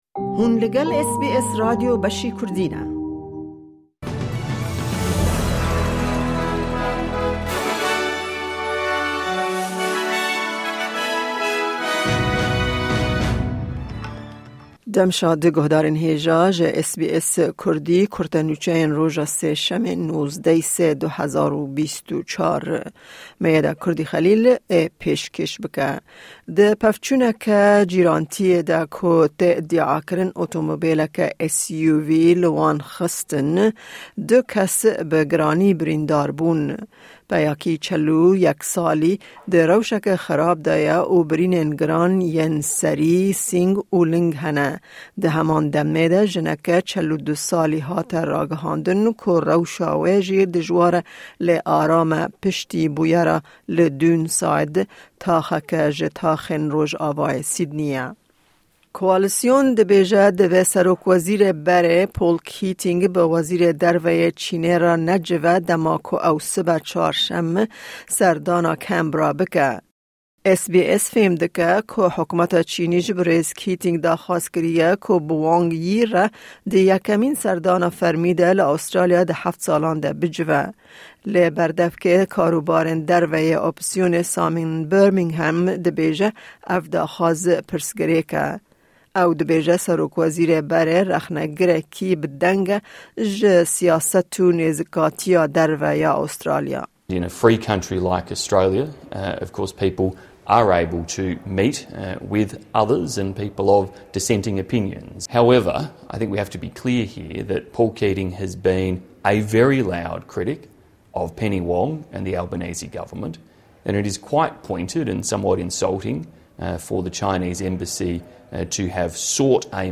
Kurte Nûçeyên roja Sêşemê 19î Adara 2024